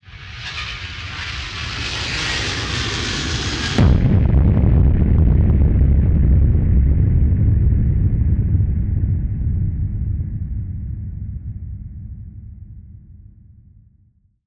BF_DrumBombDrop-01.wav